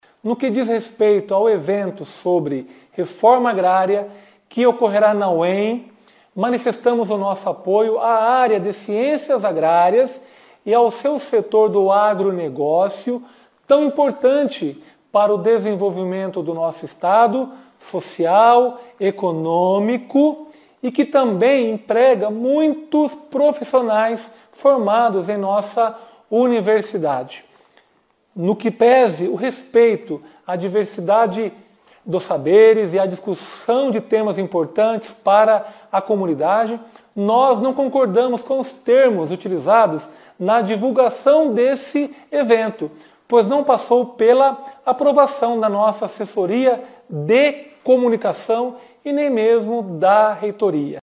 E nesta quarta-feira (11), o reitor Leandro Vanalli se manifestou sobre o assunto nas redes sociais.